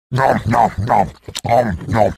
Play, download and share heavy tf2 eating sandvich original sound button!!!!
heavy-tf2-eating-sandvich.mp3